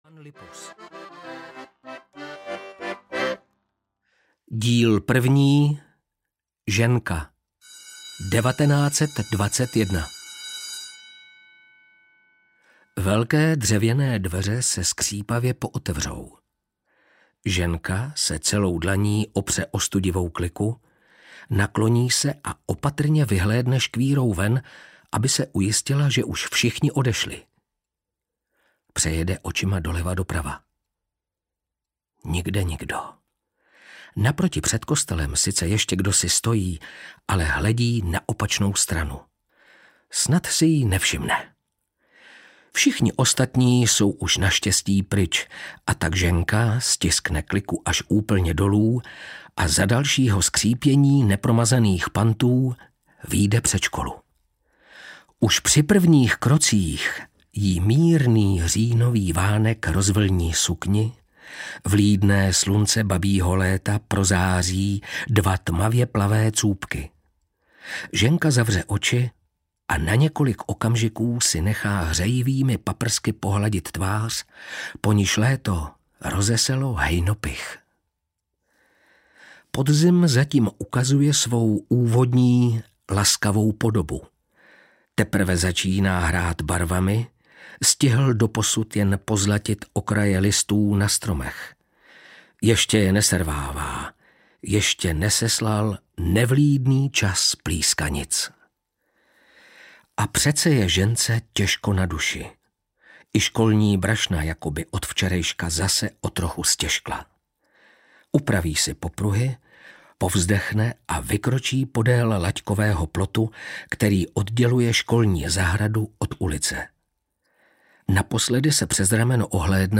Šikmý kostel 2 audiokniha
Ukázka z knihy